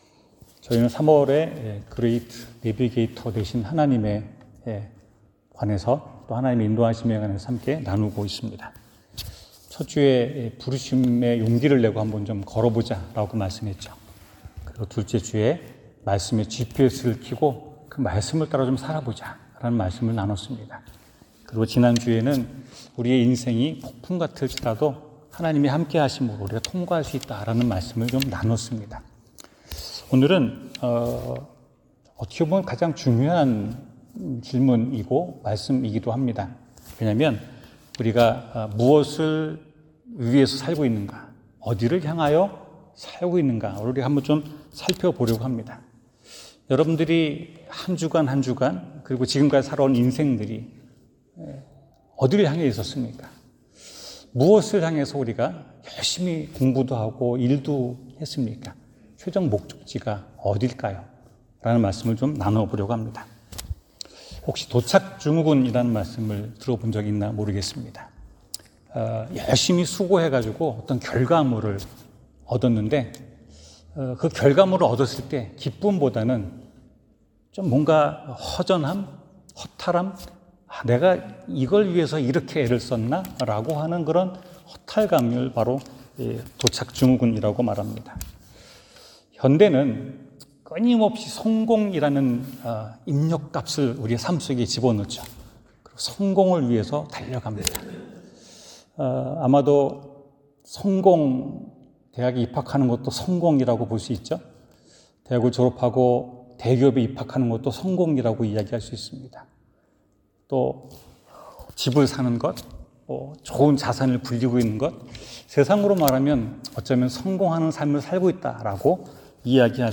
성공이 아닌 성취를 향하여 성경: 빌립보서 3:12-14 설교